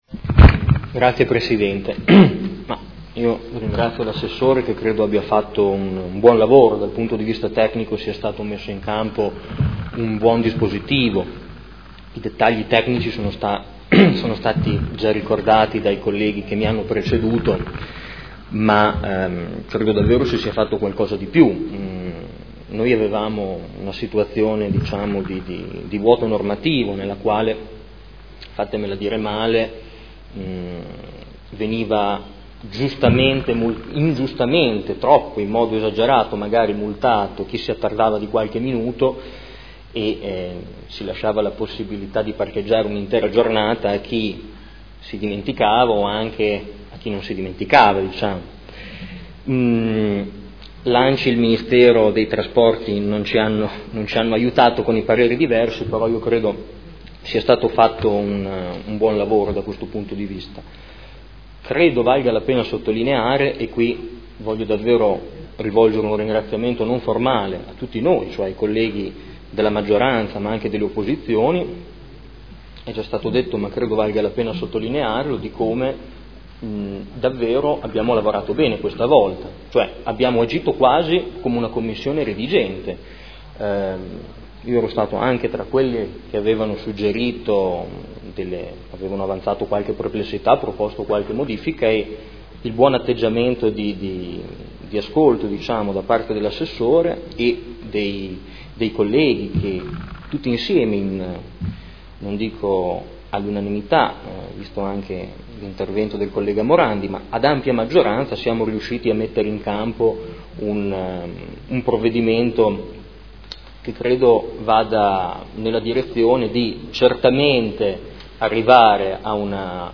Seduta del 09/07/2015 Dibattito. Approvazione del Regolamento per l’utilizzo delle aree di parcheggio a pagamento su strada.